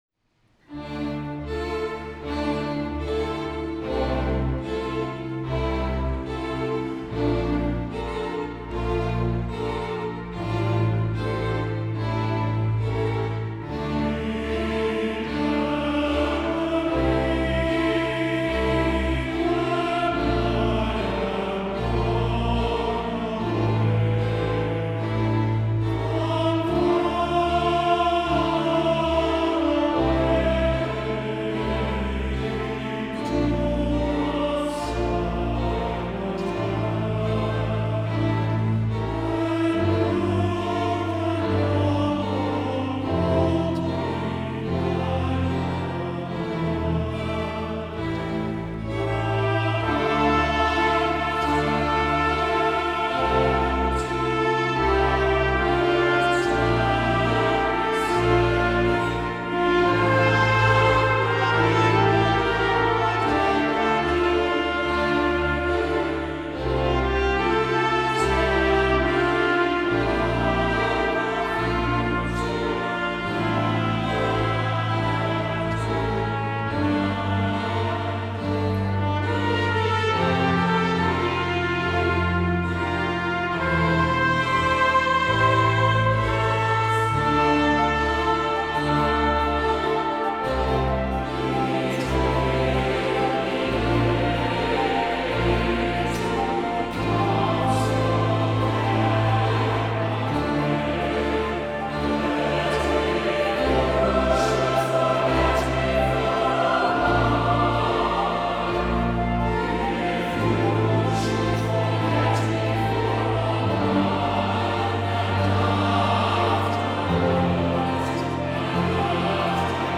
• Tags Audio, Classical, Recorded Live